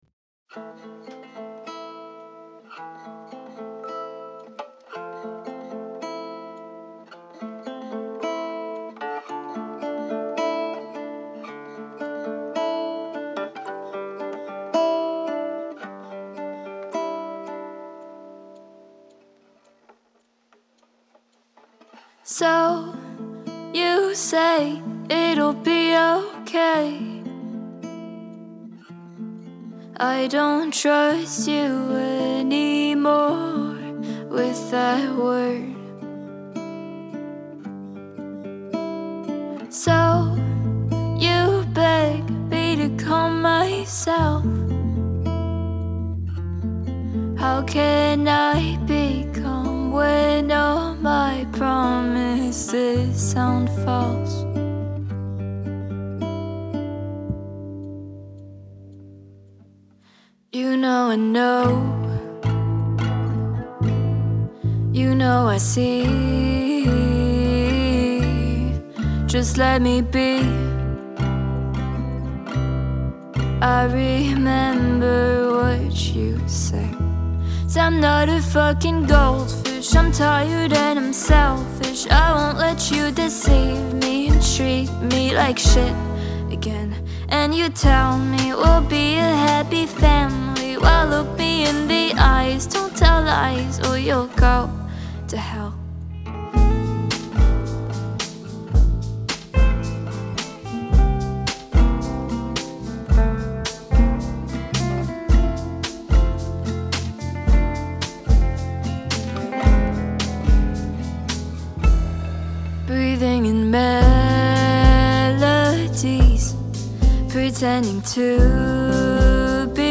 Her first finished track, called “Goldfish,” is a powerful proof of concept – a wrenching refutation of a damaging relationship that floats over a warily picked guitar and a production haunted by echoes.